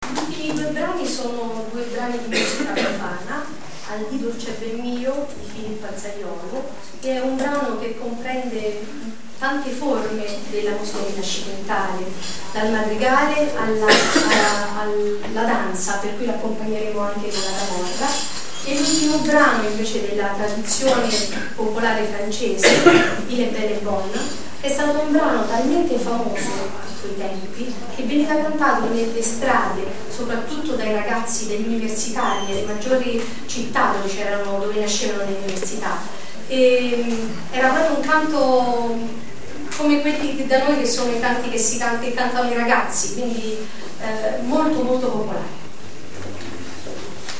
Presentazione dei brani